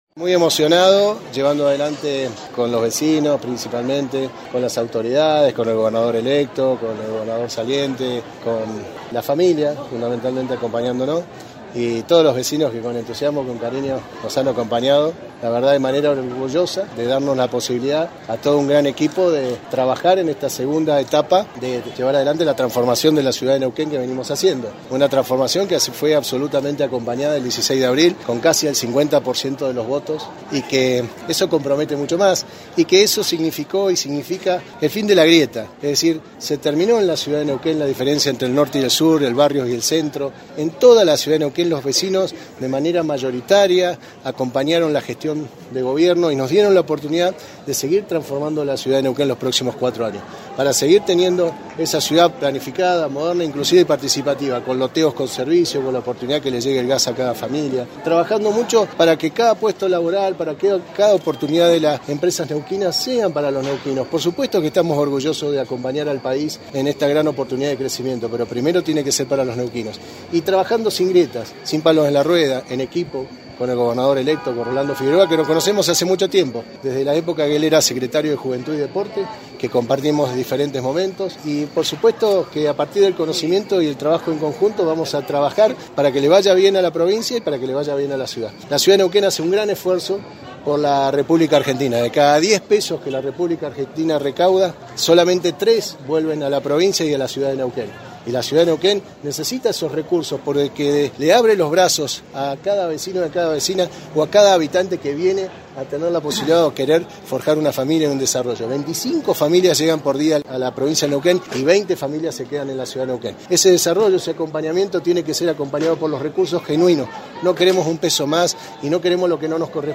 Mariano Gaido juró este domingo, por segunda vez, como intendente de la ciudad de Neuquén en el recinto del Concejo Deliberante colmado de vecinos y vecinas de la ciudad de Neuquén que lo recibieron con aplausos y ovaciones.
Mariano-Gaido-EDITADO-Intendente.mp3